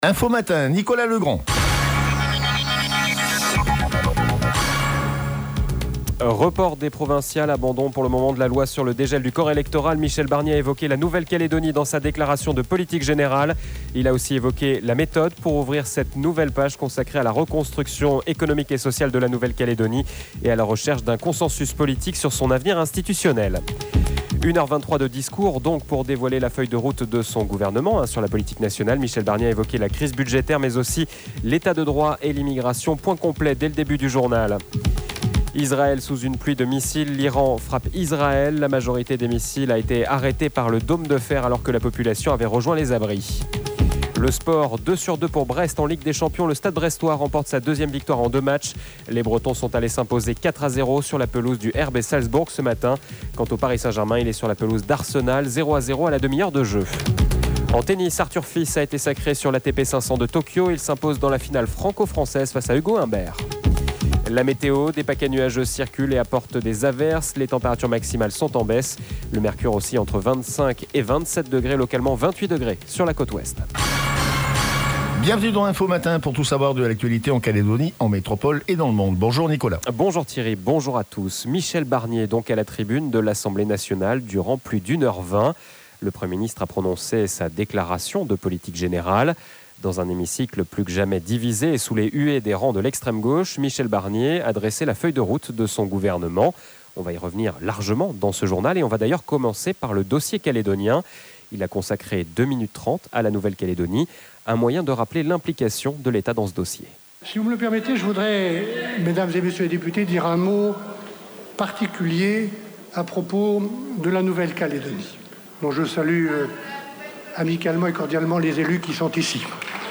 JOURNAL : INFO MATIN 02/10/24